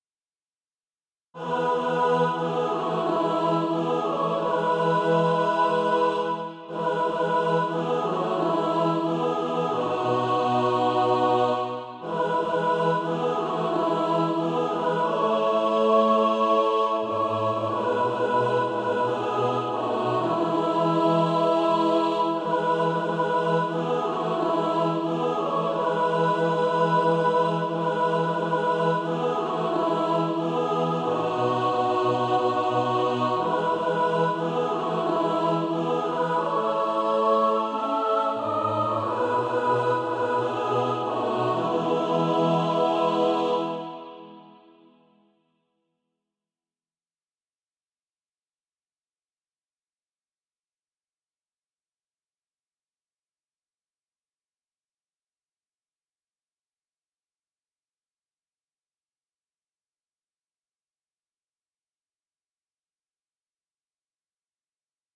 (SATB) Author
Practice then with the Chord quietly in the background.